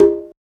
edm-perc-30.wav